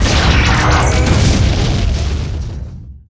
星际争霸音效-protoss-carrier-pcadth01.wav